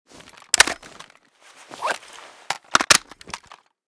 val_reload.ogg.bak